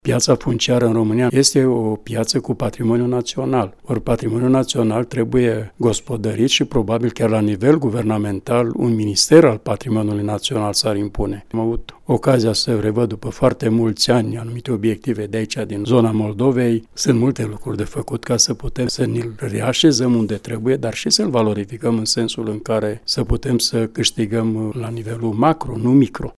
Într-o serie de interviuri